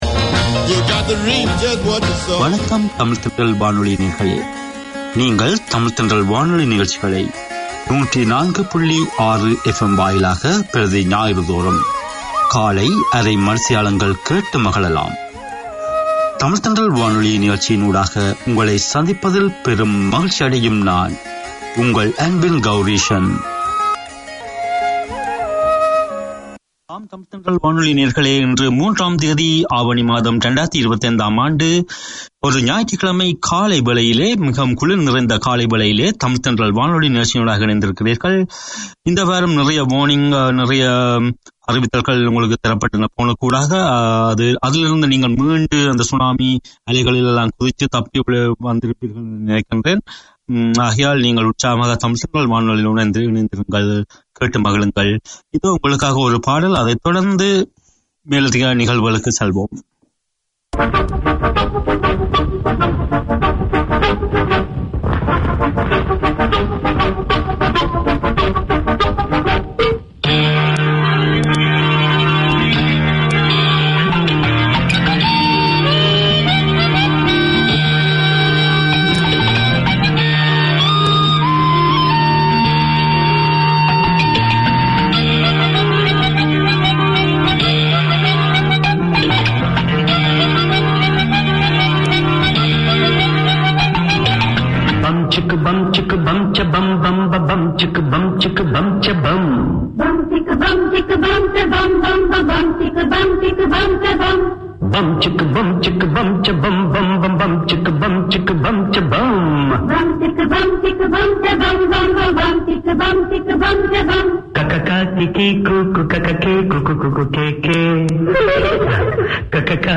Hear guests on current topics, who speak candidly about what's good and what's not good for their mental health.